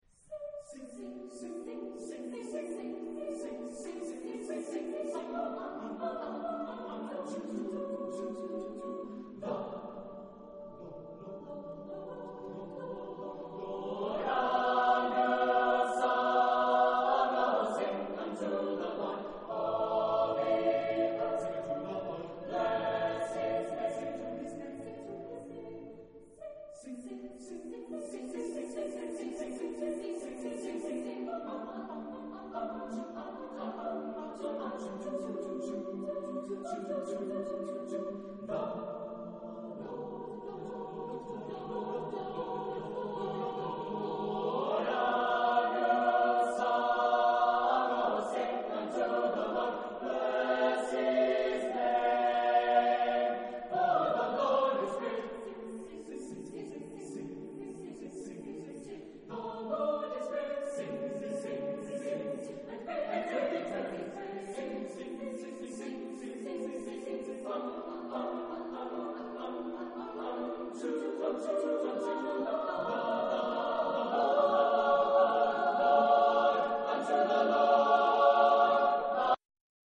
Género/Estilo/Forma: Sagrado ; Salmo ; Swing
Carácter de la pieza : rápido ; alegre ; swing ; disonante
Tipo de formación coral: SATB (div)  (4 voces Coro mixto )